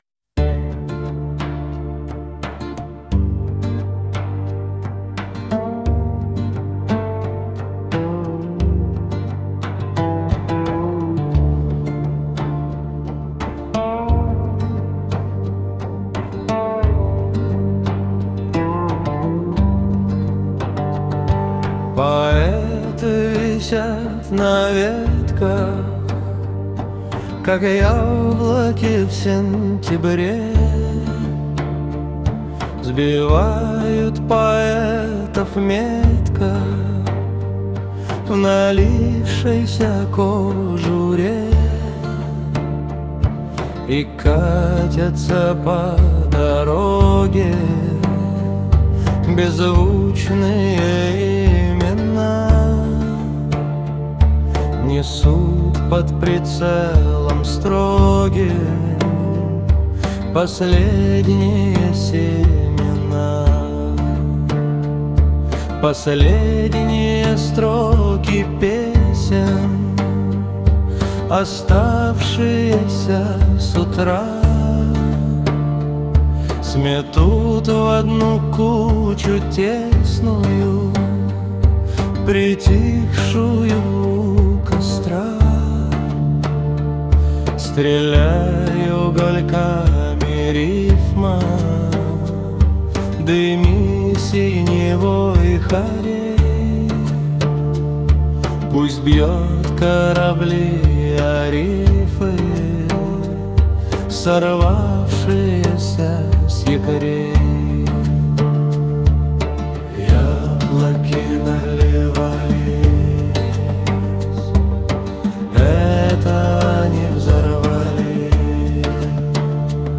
Один - мультиинструменталист, другой - бас и ударные.
Фолк